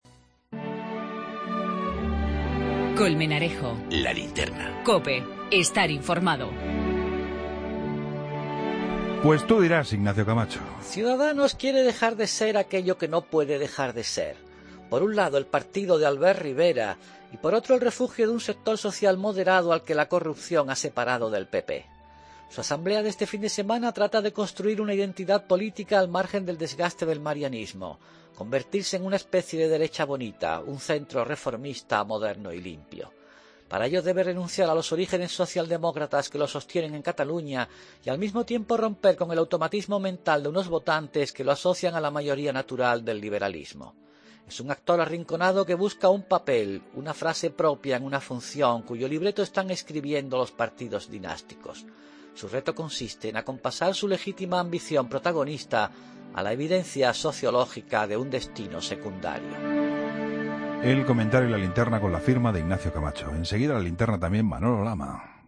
El comentario de Ignacio Camacho en 'La Linterna' ante la celebración este fin de semana en Coslada la IV Asamblea General de Ciudadanos